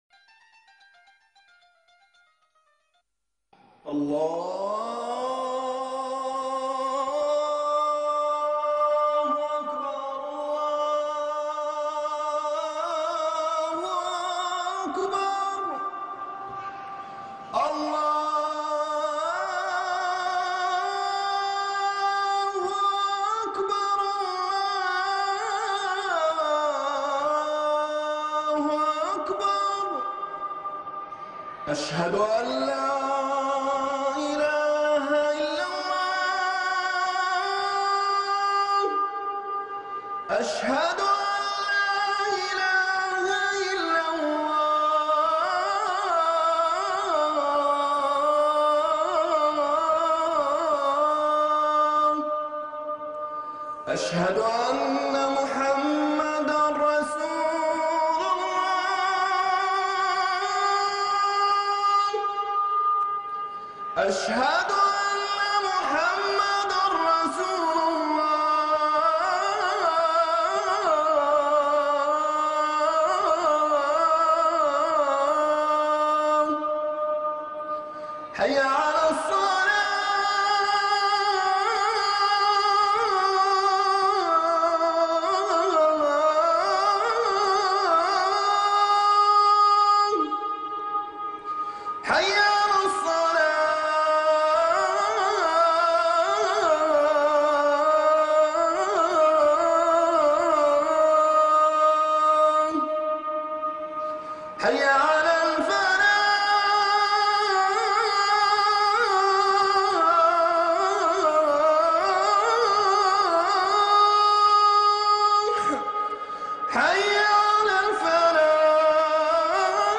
الأذان بصوت مؤذن من الإمارات